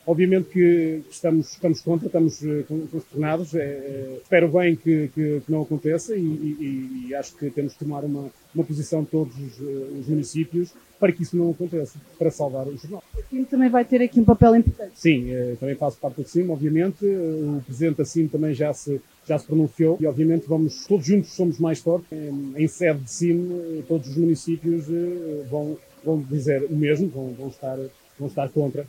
O presidente da Câmara Municipal de Macedo de Cavaleiros, Sérgio Borges, também se mostrou contra esta reconfiguração das rotas de distribuição: